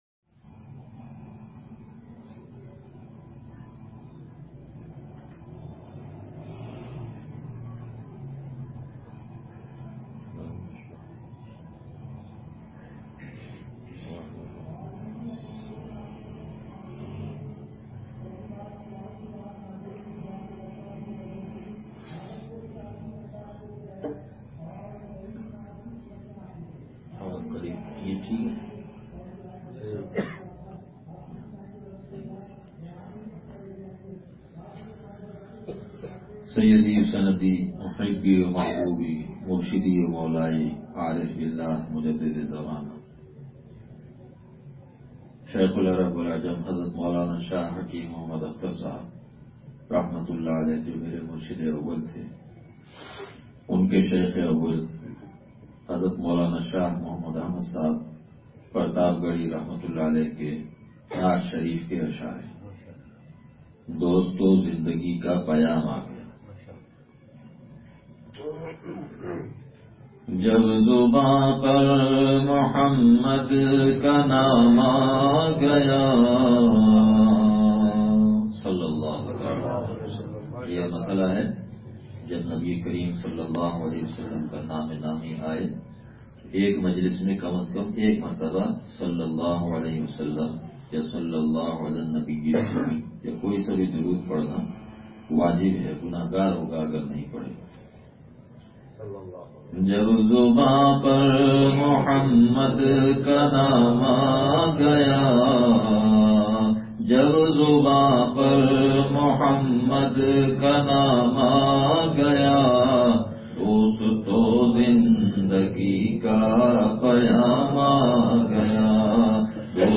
بیان – لانڈھی